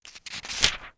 This page contains a sound effect asset in .wav format to download.
Page Flip #5
page-flip-5.wav